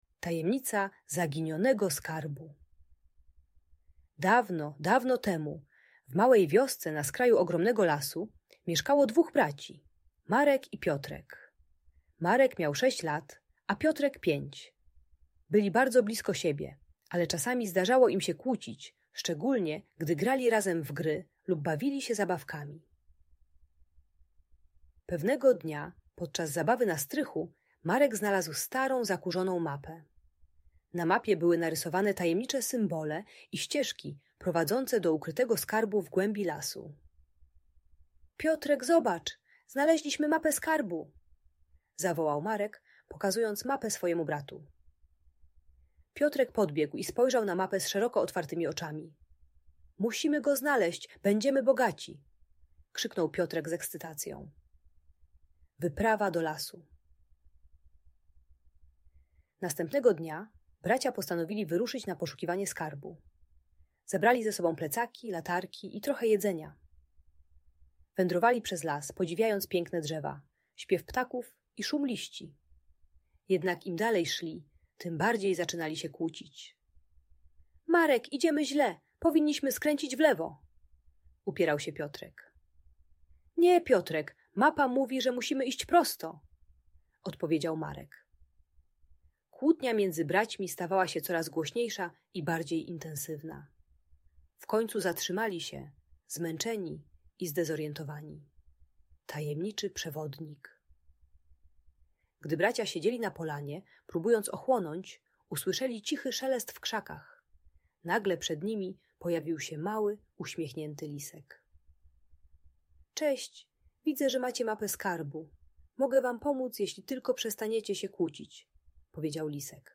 Tajemnicza historia o zaginionym skarbie - Audiobajka dla dzieci